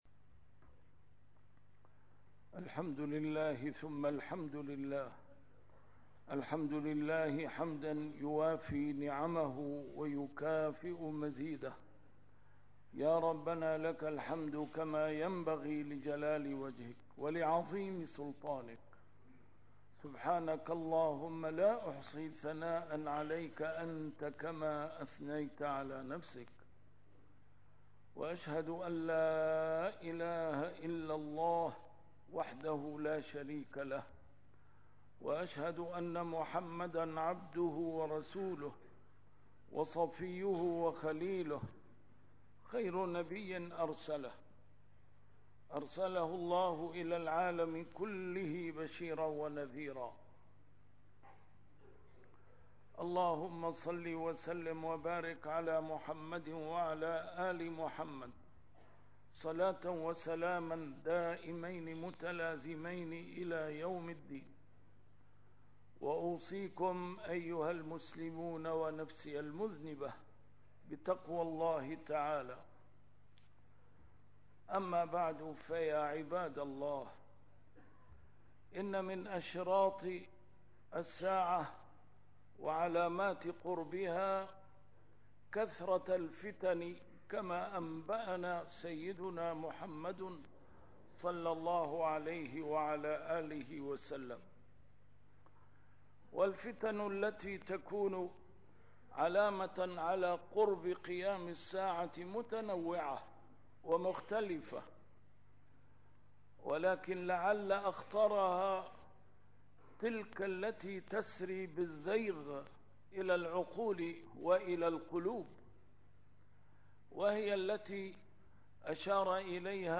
A MARTYR SCHOLAR: IMAM MUHAMMAD SAEED RAMADAN AL-BOUTI - الخطب - عندما يزيغ القلب